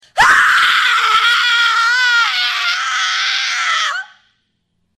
screaming-ringtone_14192.mp3